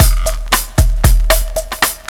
Bossa Back 03.WAV